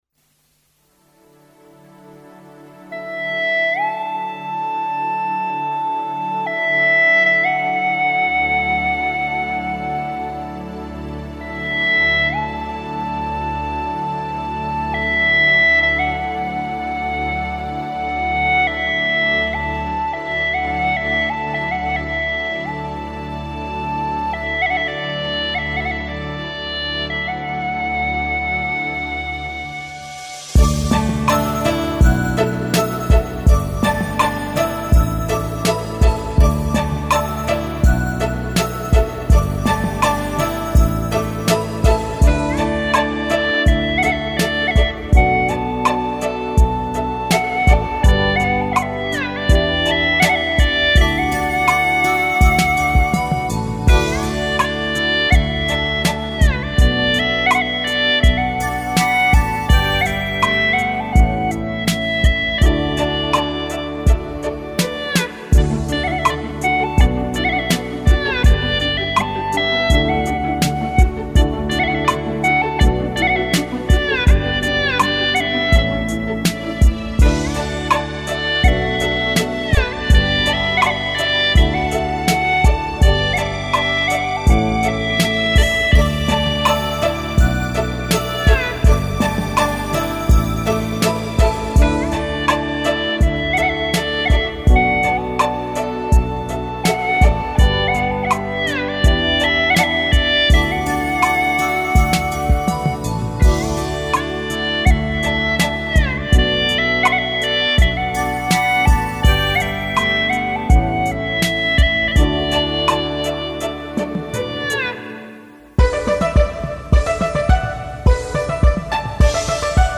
调式 : D 曲类 : 独奏